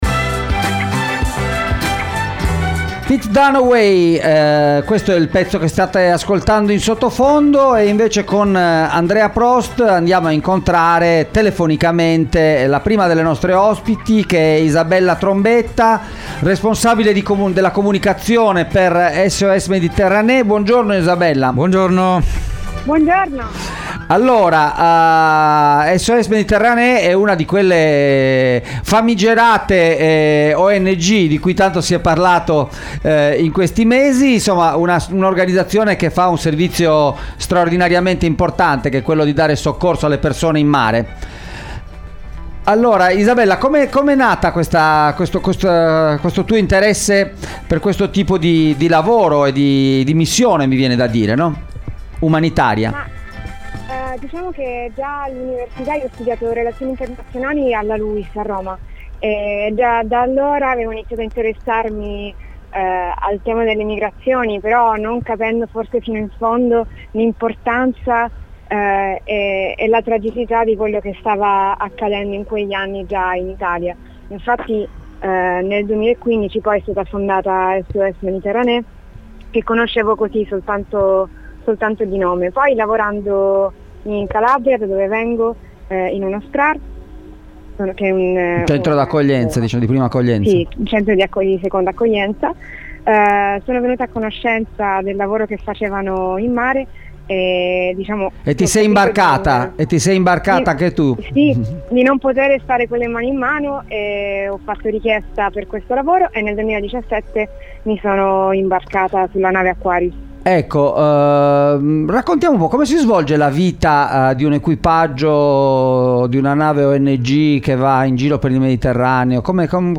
Soccorso in mare a bordo di una ONG – intervista